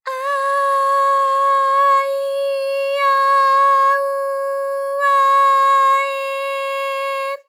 ALYS-DB-001-JPN - First Japanese UTAU vocal library of ALYS.
a_a_i_a_u_a_e.wav